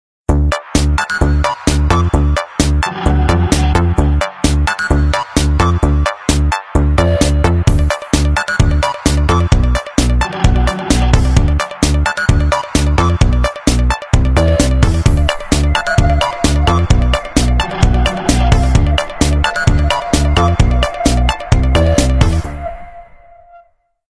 nokia-ringmod_24681.mp3